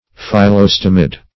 Phyl*los"to*mid\ (f[i^]l*l[o^]s"t[=o]*m[i^]d)